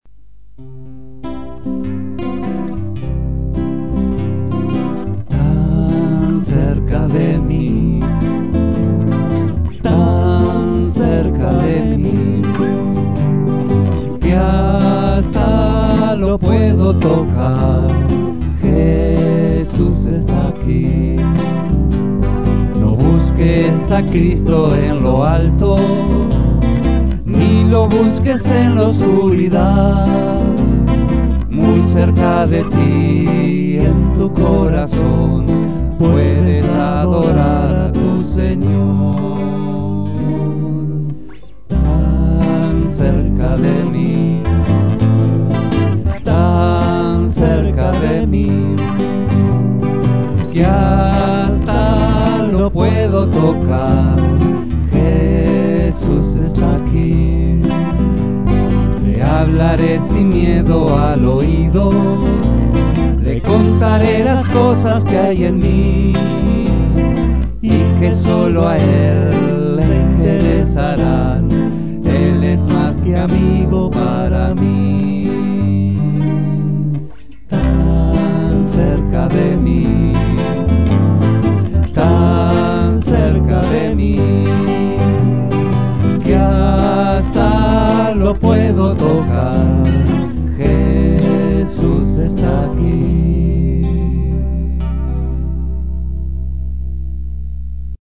voz y guitarra
bajo